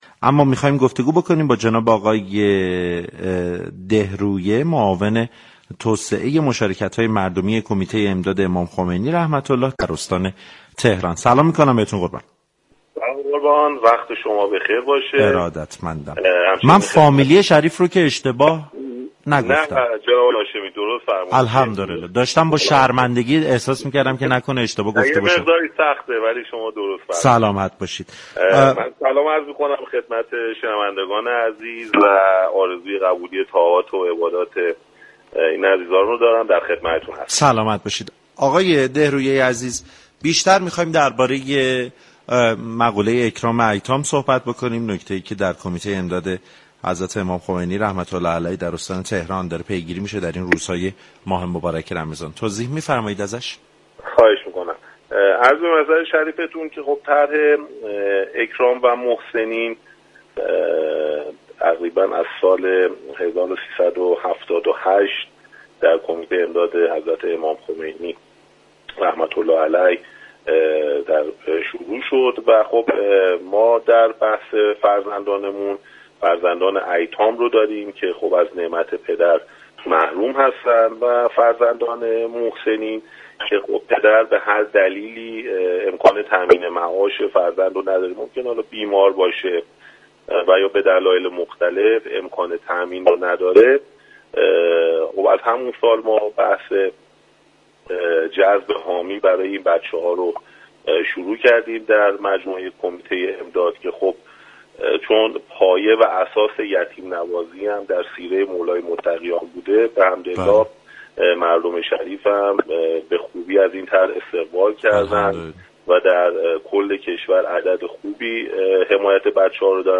در گفتگو با برنامه سعادت آباد